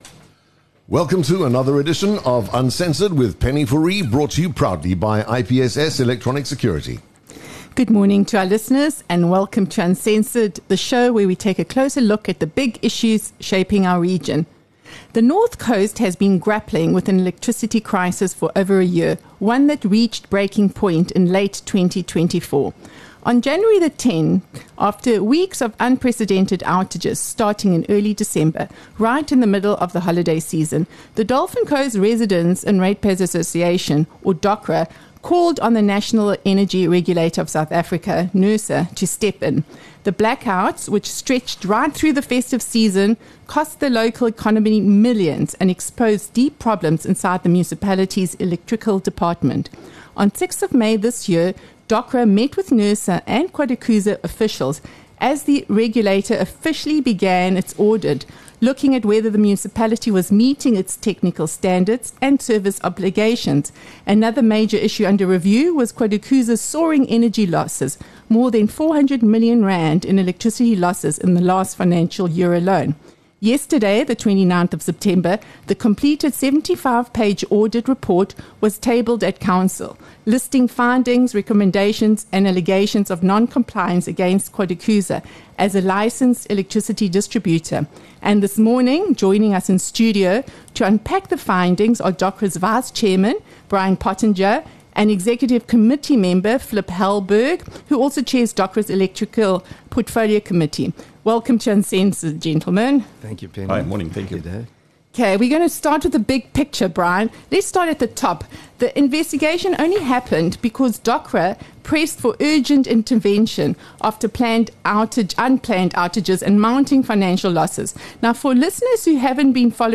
🎧 A conversation not to be missed - listen to the full episode here: